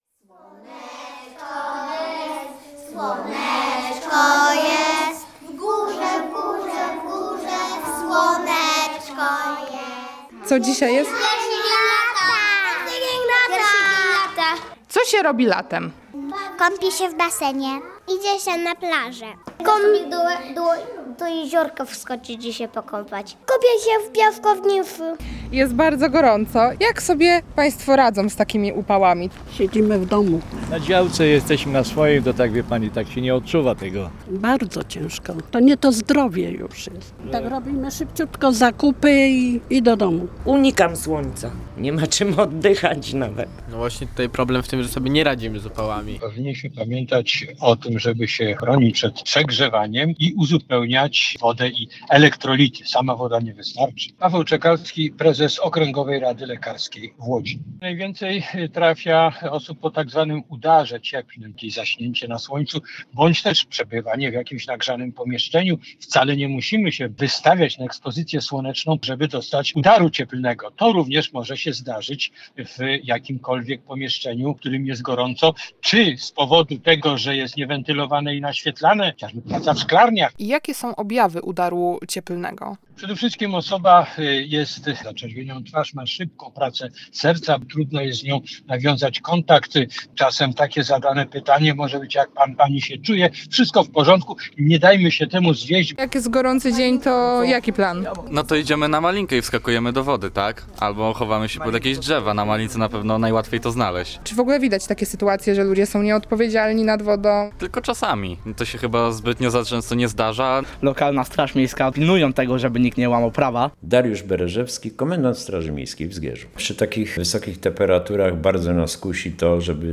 Łodzianie opowiedzieli nam, jak go spędzają.